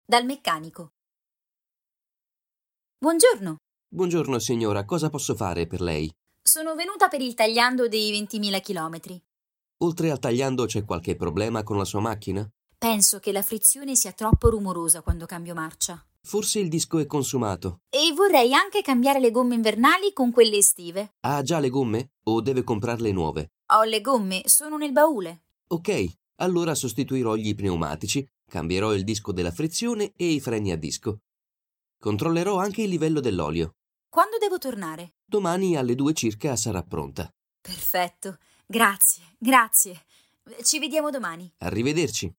hasznos párbeszédek audióval